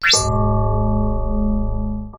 UI_SFX_Pack_61_41.wav